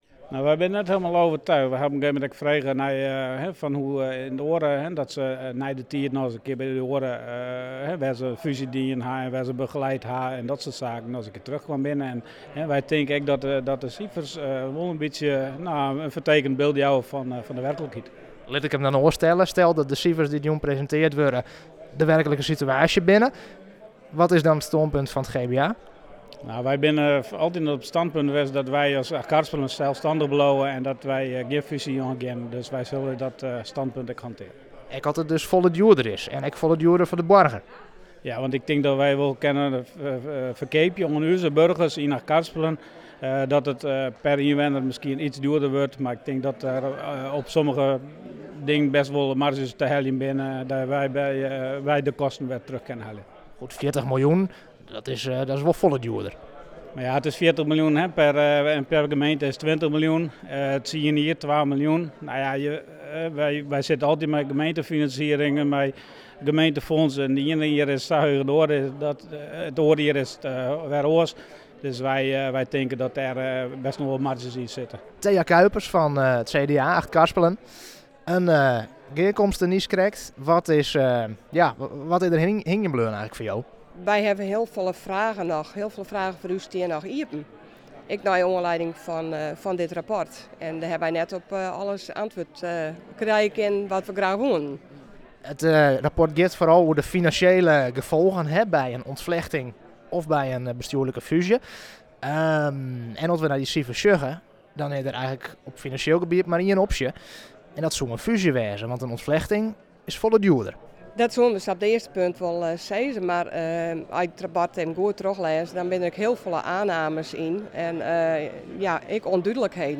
Raadslid Edwin de Vries van het GBA in Achtkarspelen en Thea Kuipers van het CDA in Achtkarspelen: